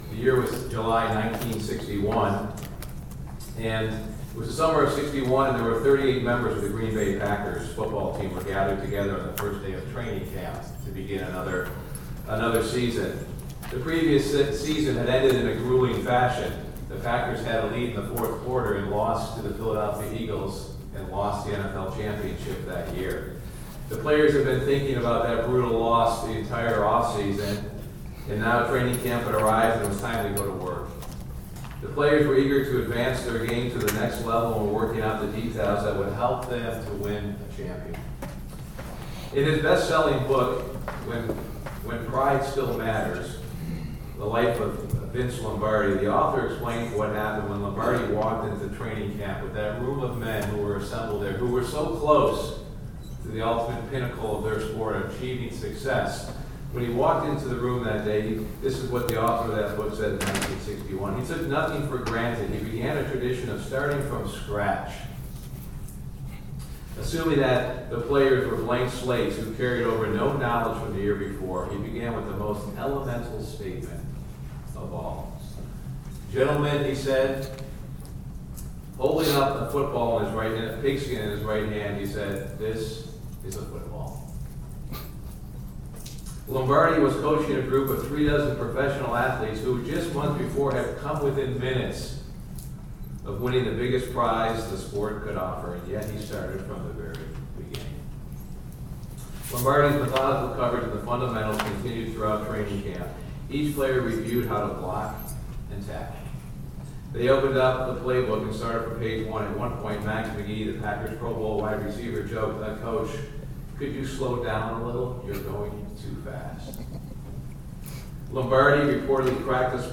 Sermons
Given in Ft. Wayne, IN